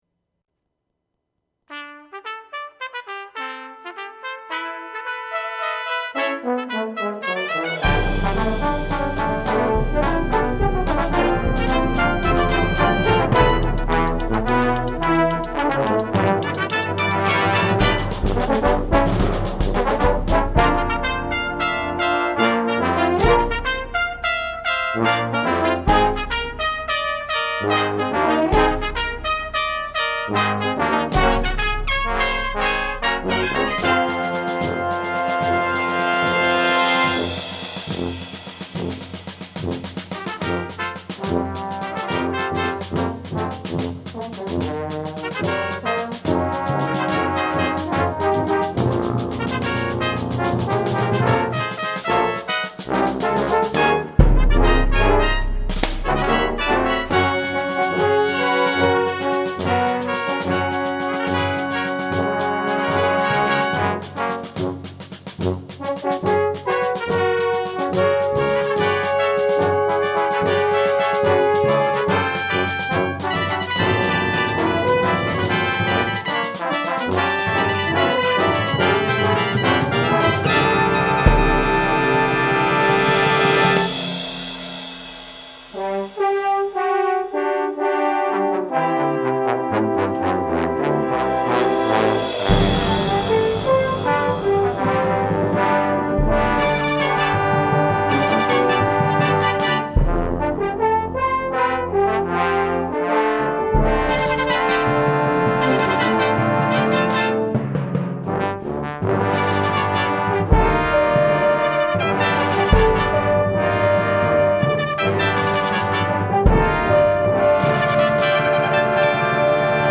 Voicing: 19 Brass